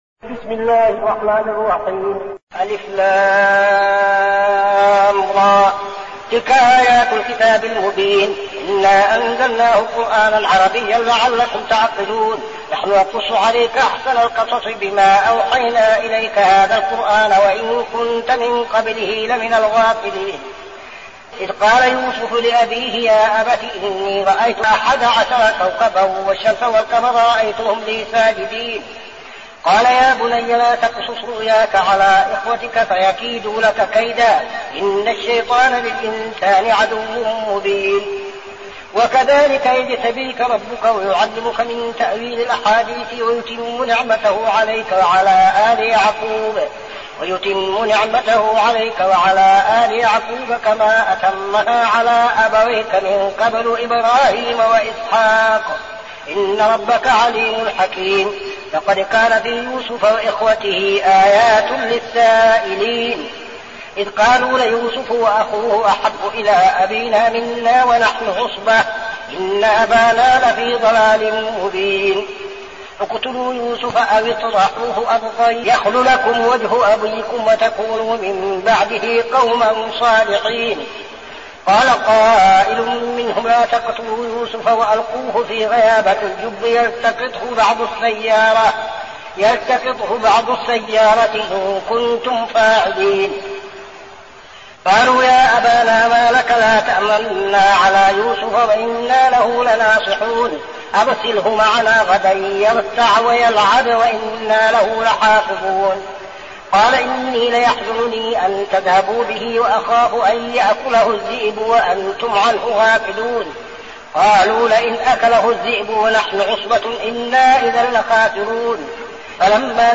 المكان: المسجد النبوي الشيخ: فضيلة الشيخ عبدالعزيز بن صالح فضيلة الشيخ عبدالعزيز بن صالح يوسف